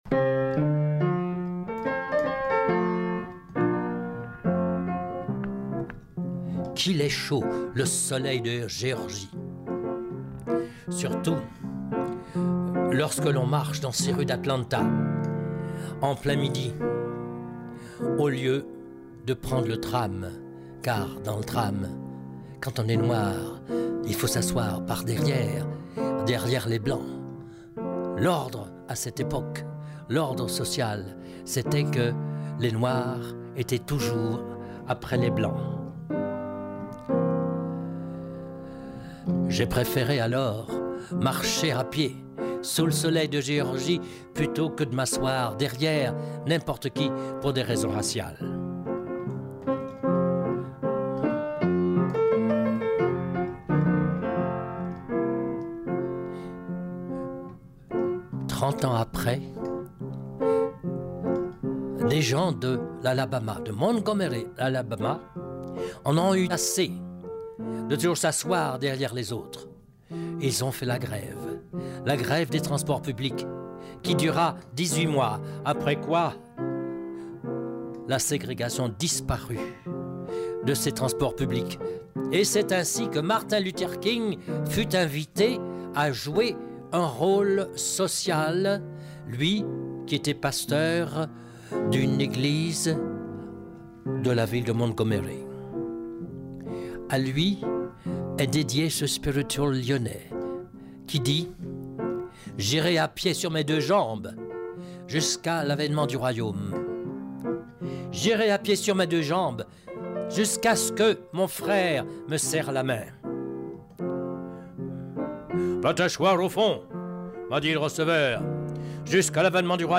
La version audio écoutable en ligne avec les commentaires et la traduction de l’auteur.